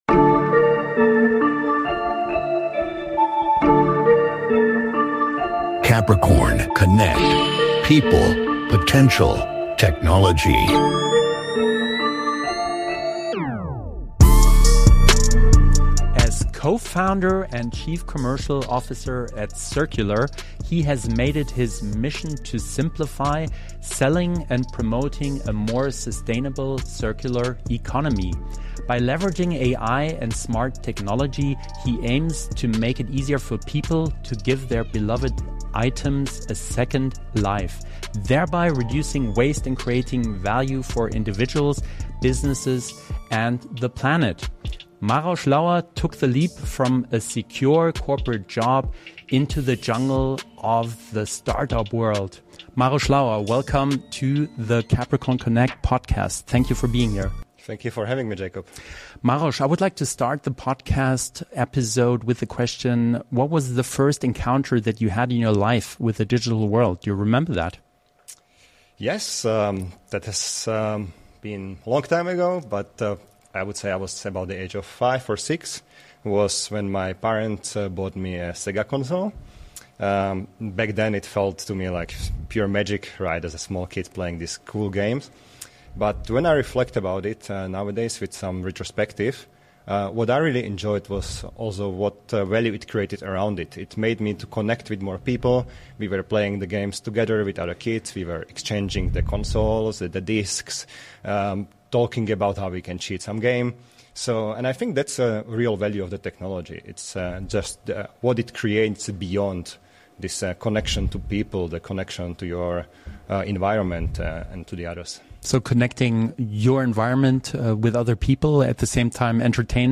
#70 - Interview